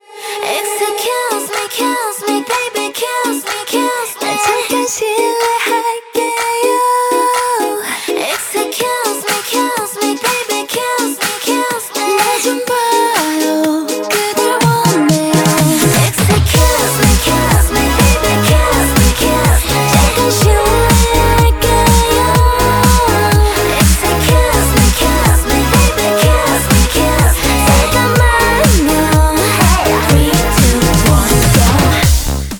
• Качество: 128, Stereo
громкие
веселые
заводные
K-Pop